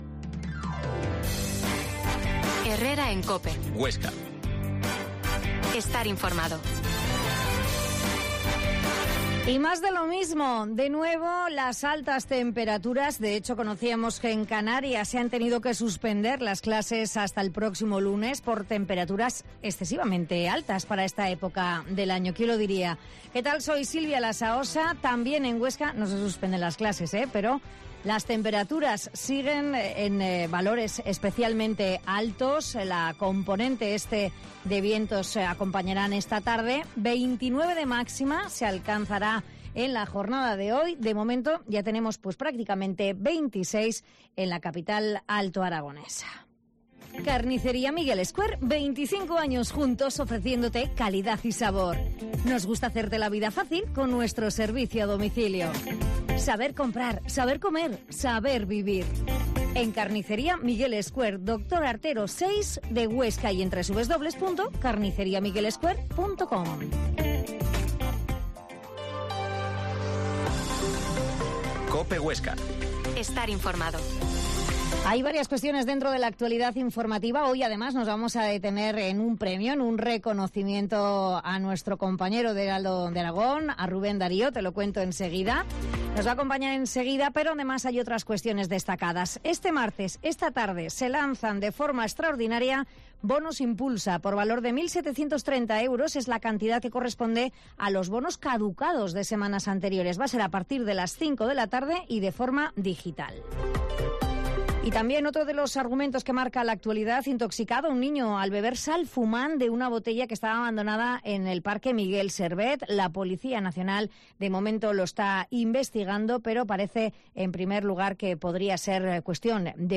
Herrera en COPE Huesca 12.50h Entrevista al periodista oscense